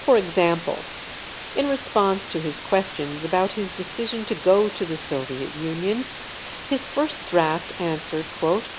gaussian_10.wav